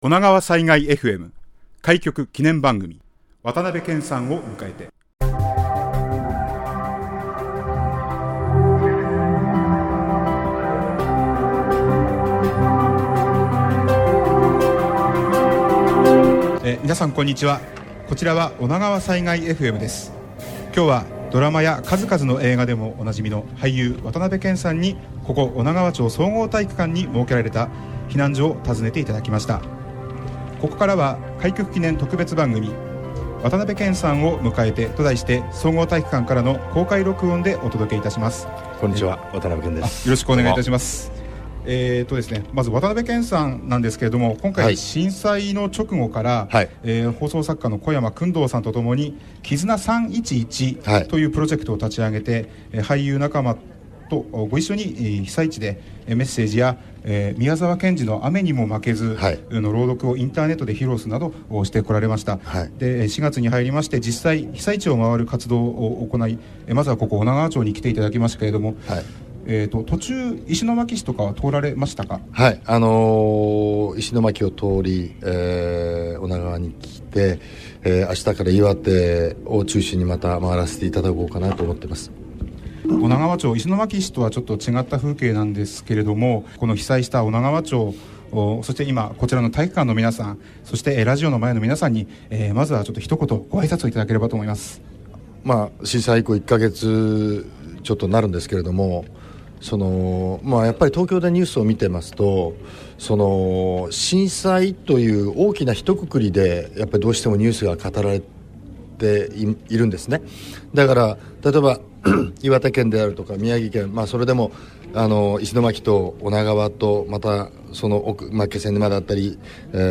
開局記念番組として放送した俳優・渡辺 謙さんと、 おながわさいがいエフエムメンバーの対談インタビューをポッドキャストとして公開します。
開局に先立ち、4/14日(木)に女川町総合体育館前のたき火スペースで野外公開録音として収録しました。